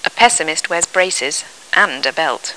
In inglese vengono scritte in corsivo quelle parole sulle quali, nel discorso, viene posto un accento enfatico (si tratta spesso di parole solitamente non accentate: verbi ausiliari, pronomi, ecc.):